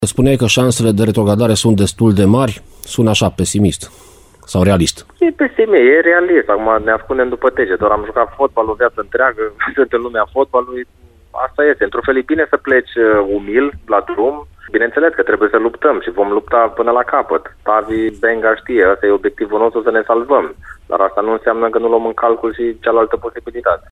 Noul director sportiv al divizionarei secunde de fotbal Politehnica Timișoara, Paul Codrea, a fost invitatul ediției de sâmbătă a emisiunii Arena Radio.